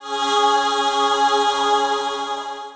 Key-choir-187.1.1.wav